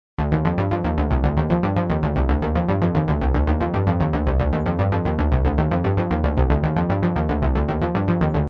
On that track there’s just one chord over 4 bars, a Cmin9/11.
So, giving a guess that this might have been a synth bass arpeggio, I get this: